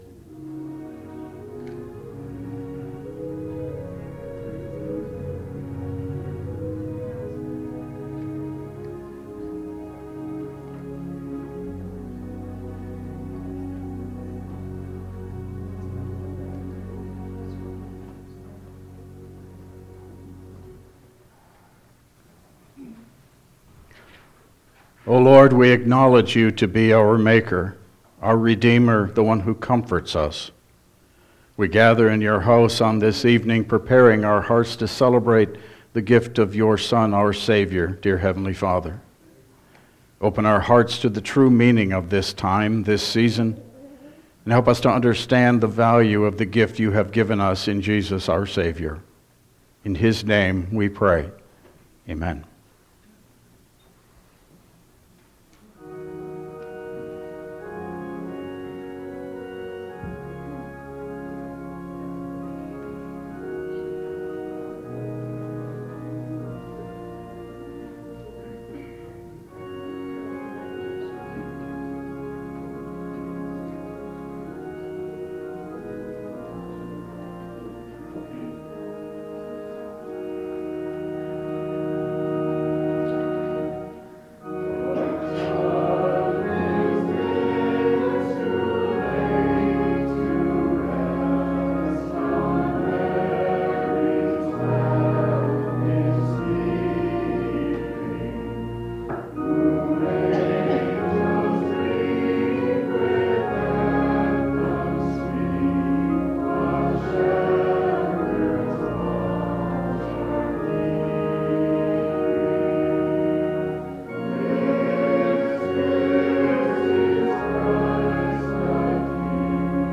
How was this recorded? Service Type: Christmas Eve